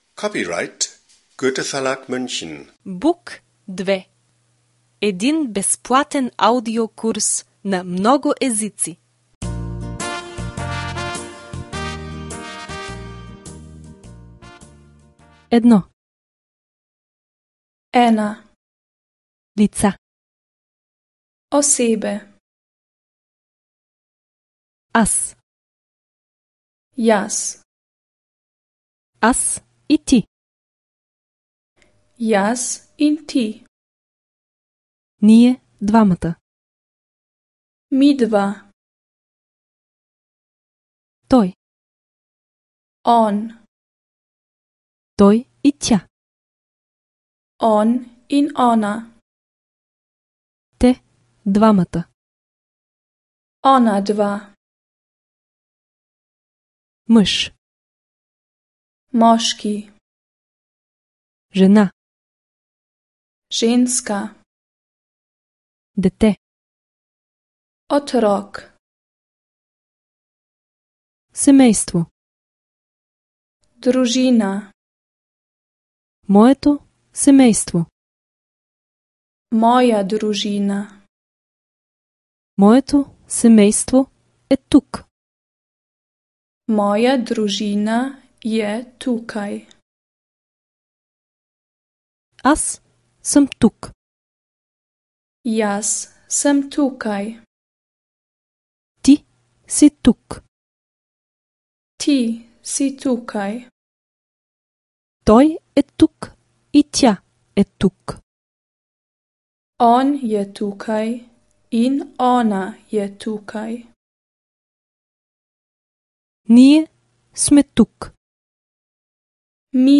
Словенско произношение (аудио)
Тук можете да чуете как звучи словенска реч от първоизточника-носител на езика. Освен това ще усвоите правилно произношение на думи и състоящи се от тях изрази.